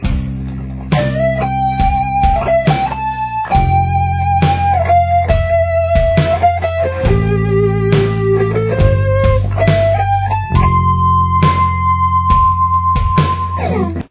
Lead
This is the harmonica parts transcribed for guitar: